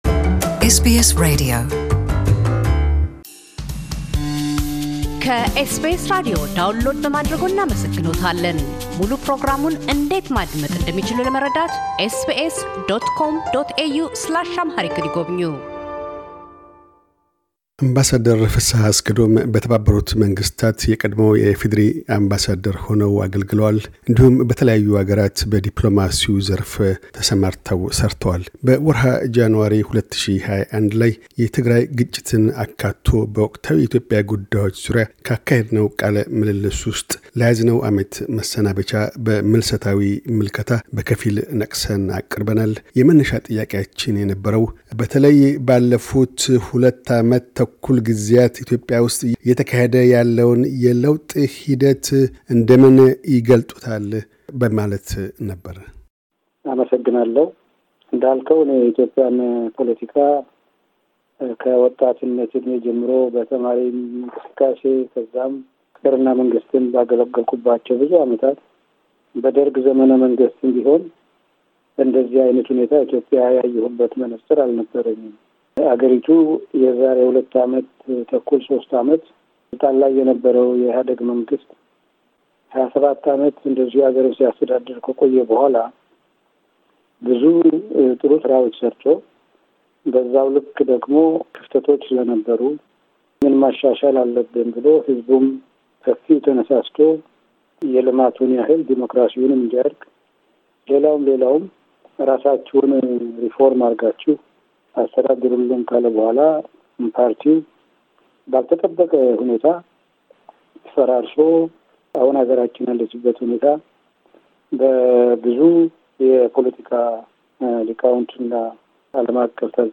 2021 ምልሰታዊ ምልከታ - አምባሳደር ፍሰሃ አስገዶም፤ በተባበሩት መንግሥታት የቀድሞ የኢፌዴሪ አምባሳደር ሆነው አገልግለዋል። እንዲሁም፤ በተለያዩ አገራት በዲፕሎማሲው ዘርፍ ሠርተዋል። በ2021 ጃኑዋሪ የትግራይን ጉዳይ አካትቶ በኢትዮጵያ ወቅታዊ ጉዳዮች ዙሪያ ካካሄድነው ቃለ ምልልስ ውስጥ ለያዝነው ዓመት መሰናበቻ በምልሰታዊ ምልከታ በከፊል ነቅሰን አቅርበናል።